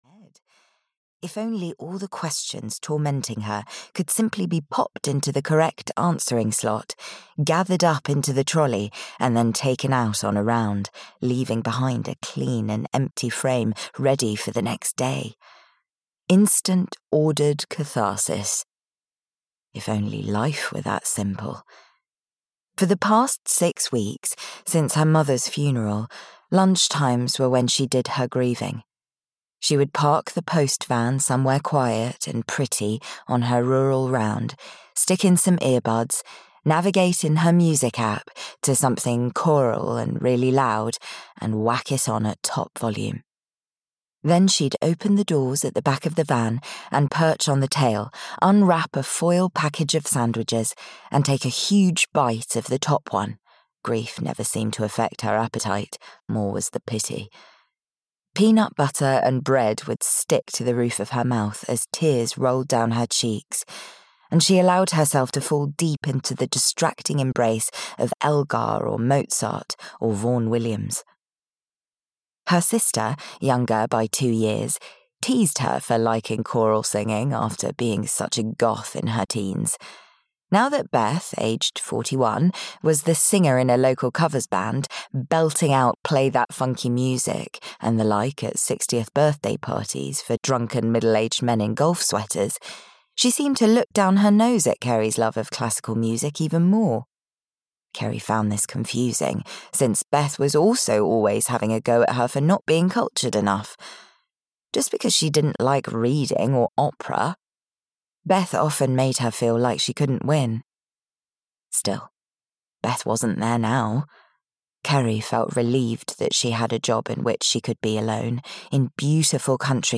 Kerry Tucker Learns to Live (EN) audiokniha
Ukázka z knihy